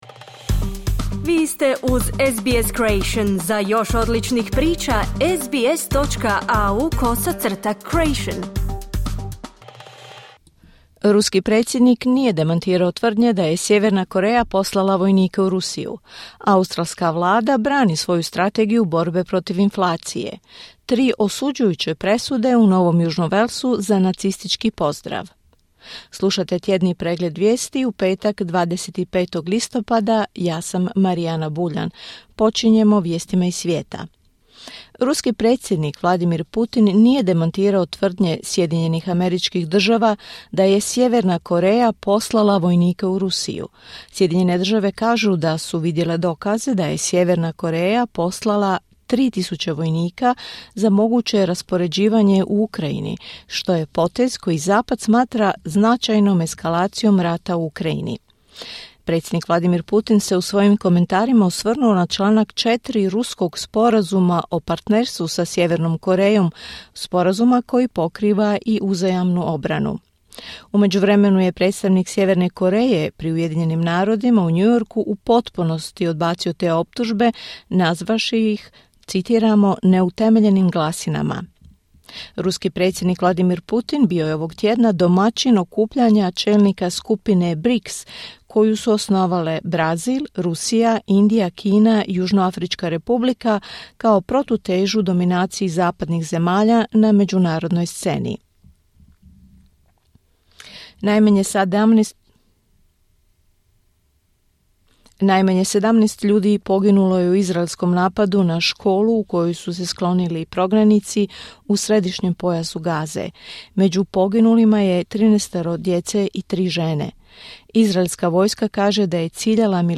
Tjedni pregled vijesti, 25.10.2024.
Vijesti radija SBS.